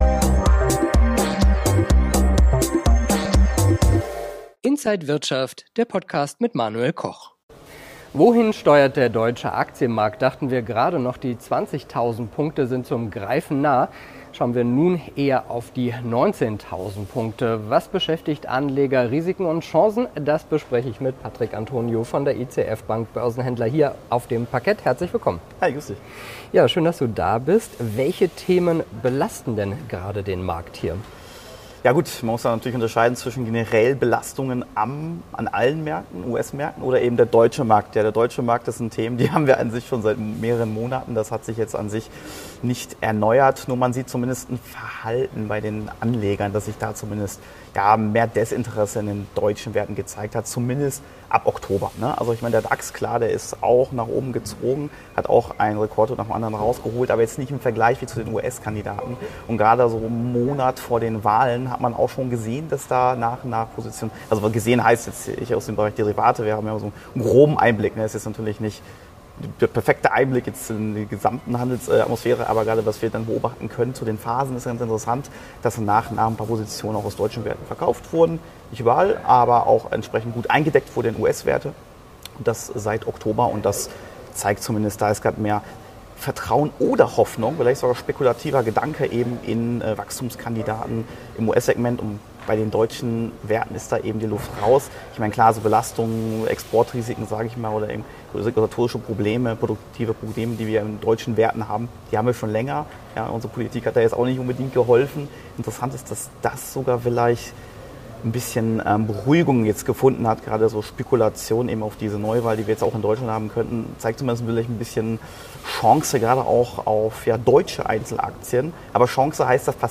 Alle Details im Interview von